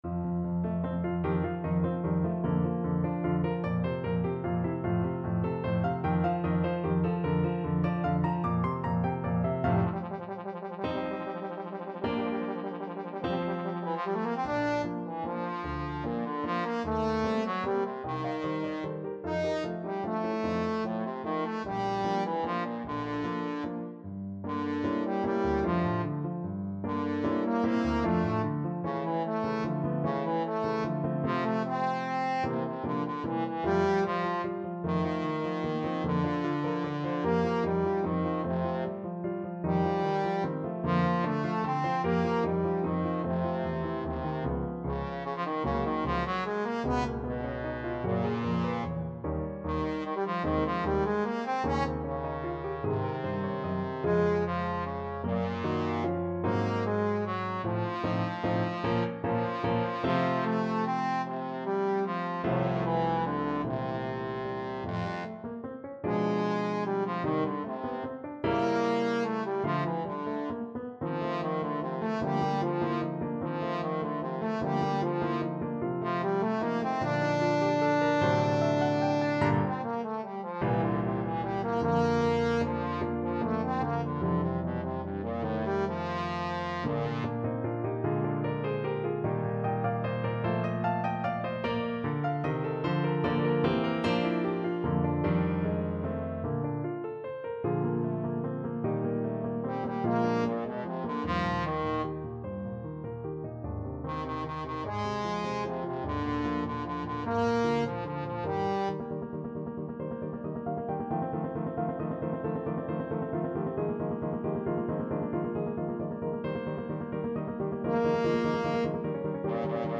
3/4 (View more 3/4 Music)
Allegretto (.=50)
Classical (View more Classical Trombone Music)